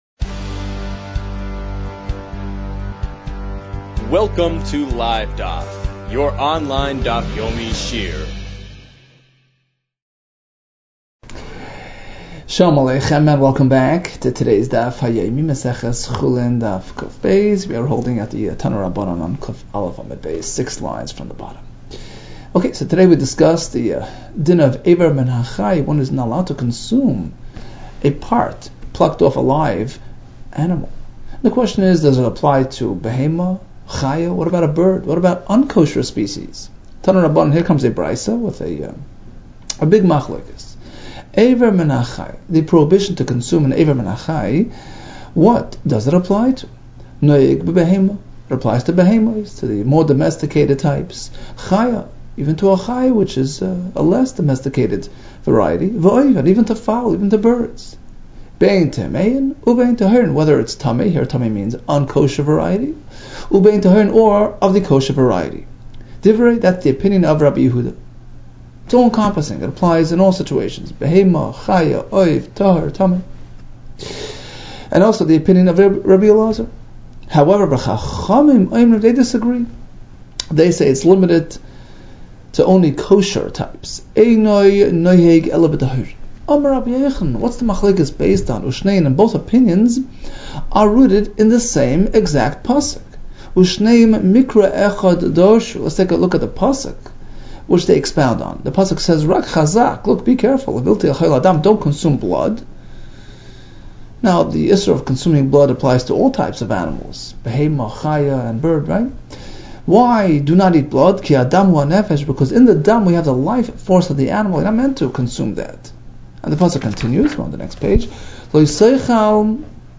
Chulin 102 - חולין קב | Daf Yomi Online Shiur | Livedaf